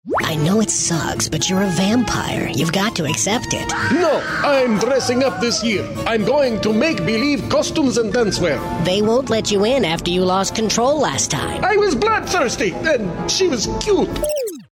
Versatile, animated, conversational, and relatable.